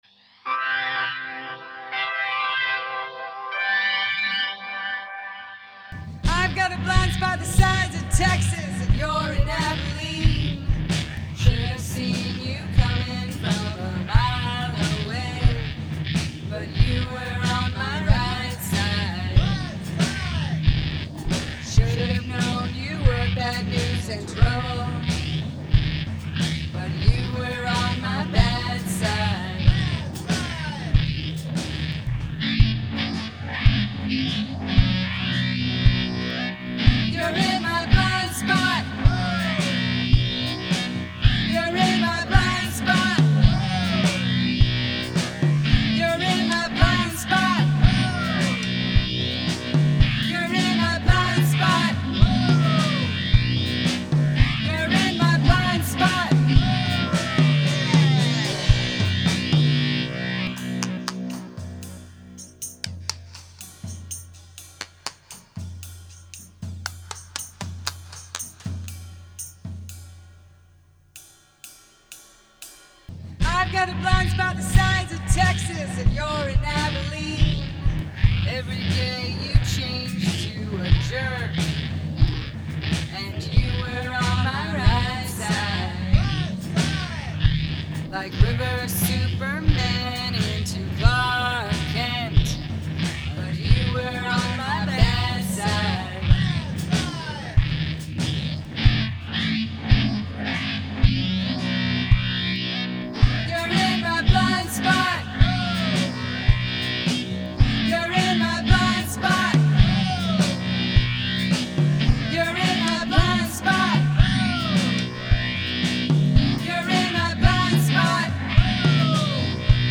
Hand percussion
vocals, Omnichord
drums, backing shouts